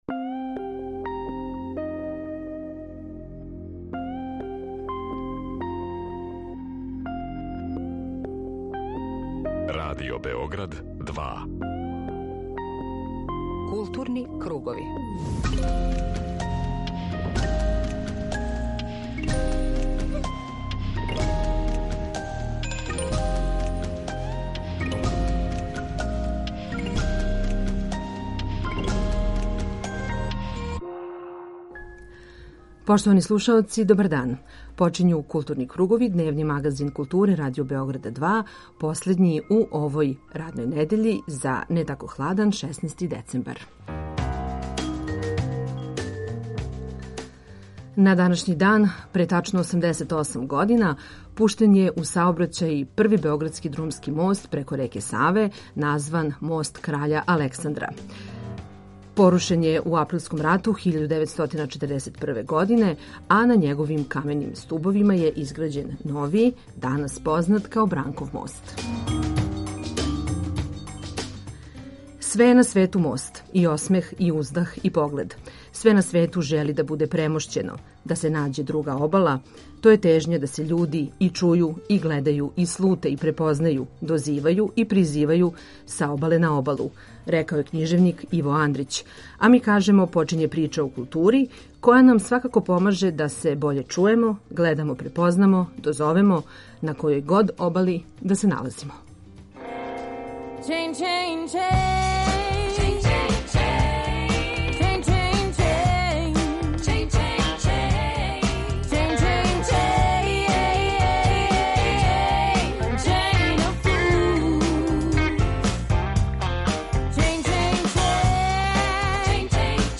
Група аутора Централна културно-уметничка емисија Радио Београда 2.
У емисији Културни кругови преносимо атмосферу са свечаног уручења награда Удружења филмских уметника Србије зе 2022. годину.